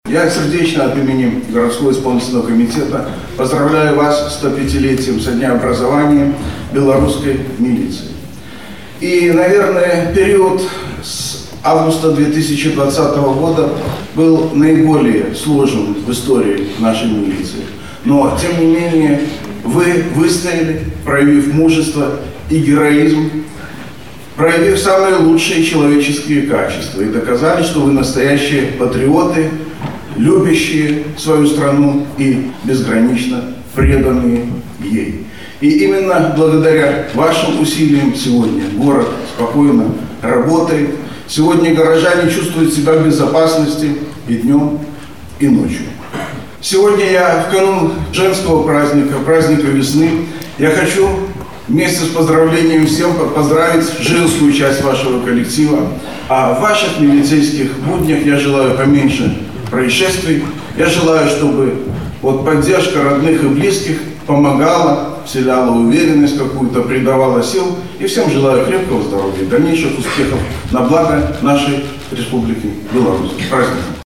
В Барановичском ГОВД прошли торжества, посвященные 105-летию белорусской милиции
Со знаменательной датой личный состав поздравил председатель горисполкома. Юрий Громаковский отметил, что несмотря на сложности последнего времени, наша милиция выстояла, проявив мужество, героизм и патриотизм.